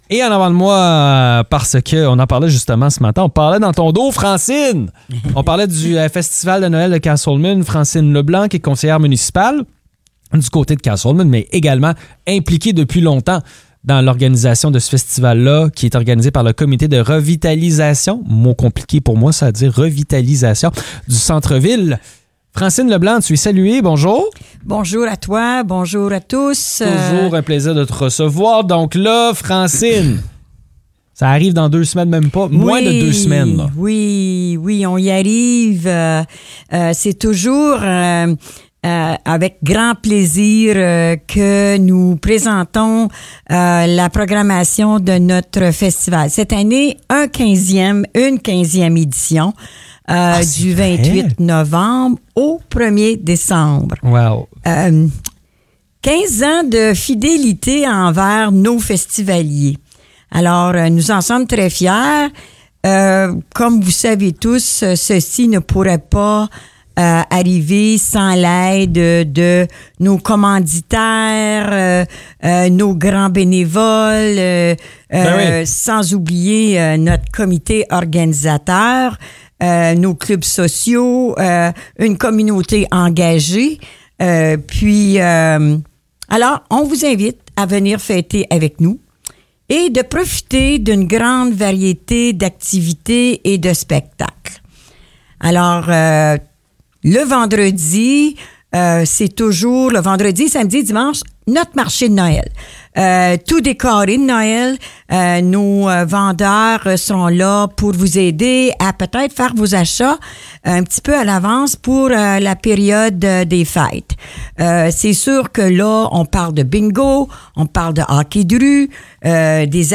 Francine Leblanc, conseillère municipale à Casselman, nous présente en détail la programmation de l'édition 2024 du Festival de Noël de Casselman.